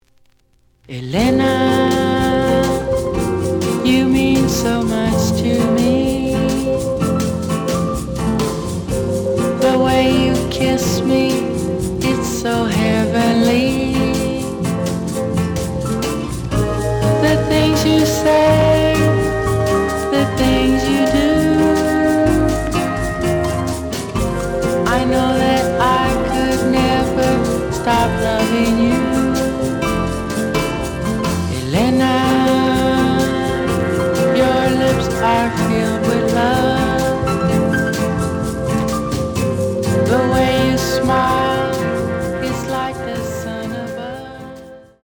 The audio sample is recorded from the actual item.
●Genre: Vocal Jazz